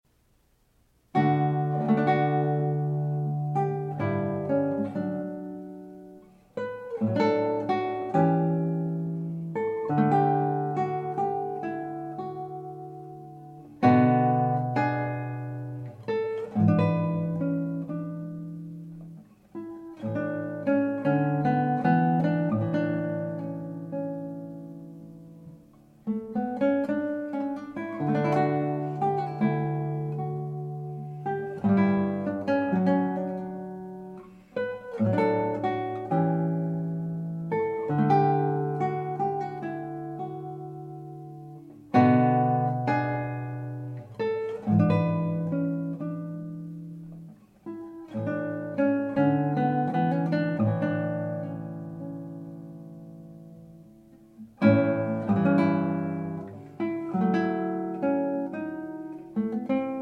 guitar
transcribed for guitar